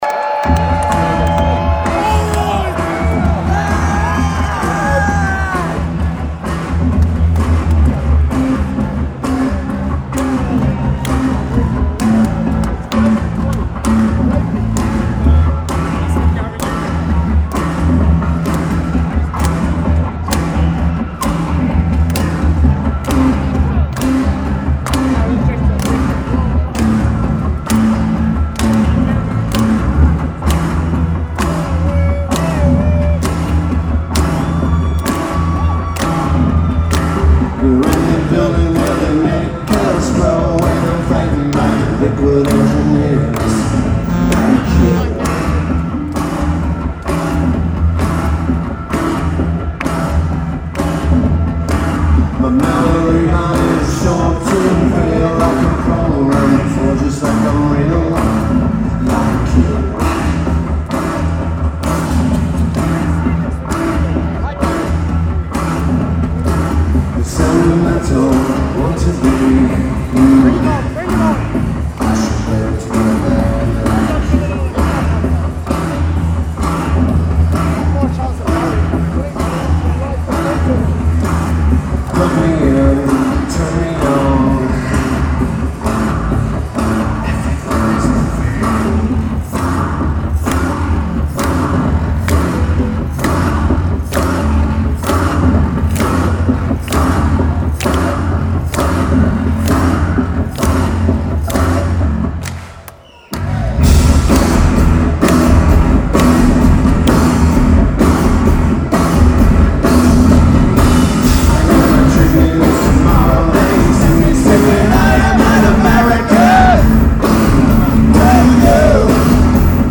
Lineage: Audio - AUD (Phone Dictation)